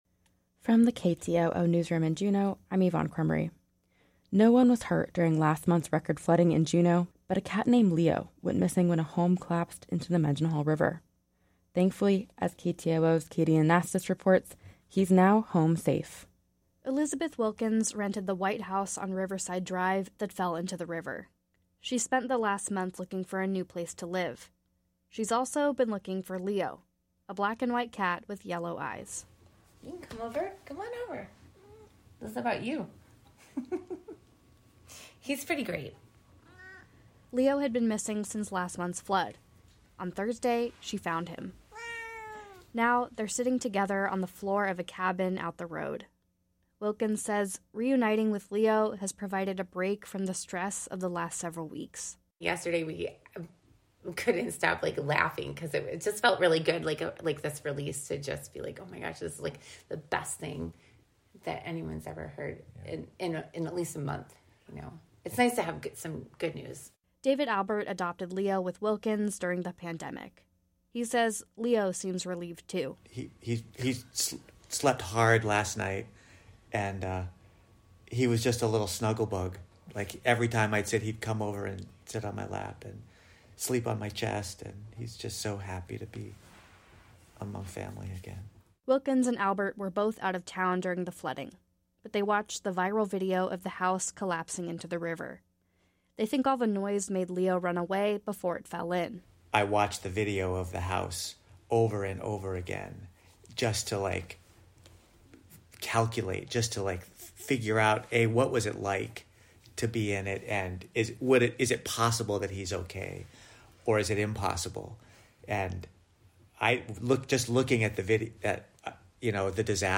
Newscast – Tuesday, Sept. 5, 2023